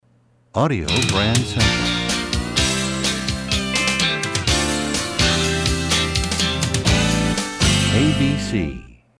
MCM Category: Radio Jingles
Genre: Jingles.